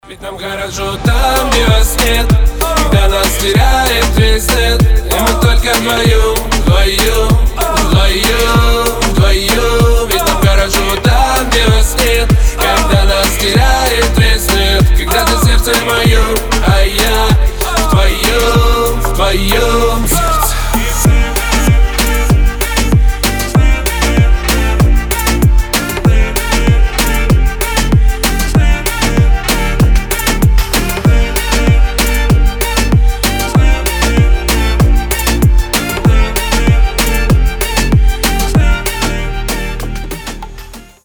поп
позитивные
заводные